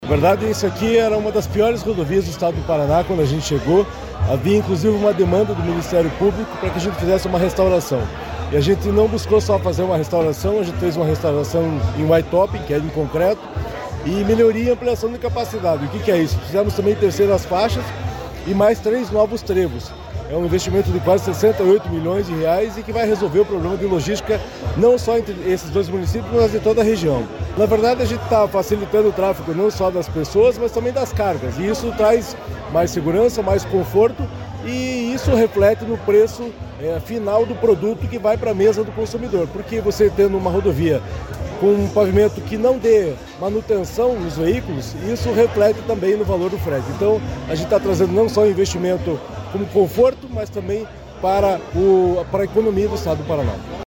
Sonora do diretor-presidente do DER/PR, Fernando Furiatti, sobre a pavimentação em concreto da PR-180